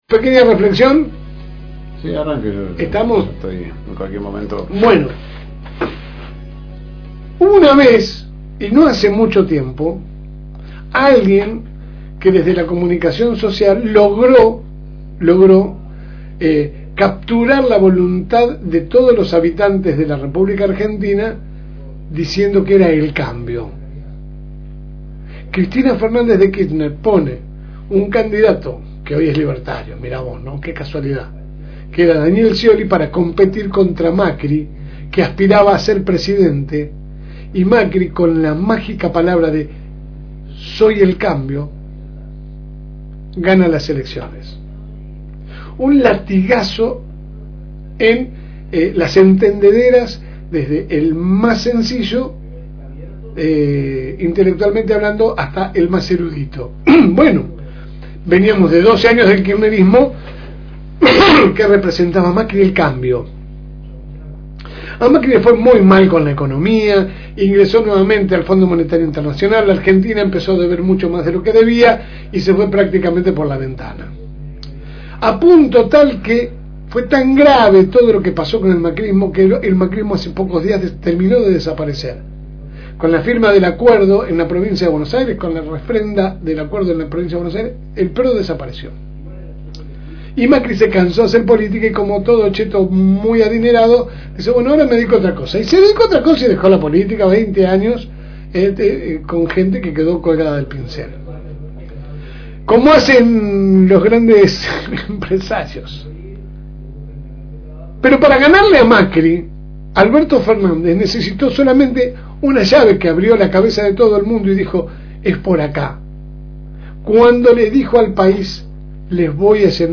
Compartimos con ustedes la editorial
Que sale por el aire de la FM REENCUENTRO 102.9 de lunes a viernes de 10 a 12 HS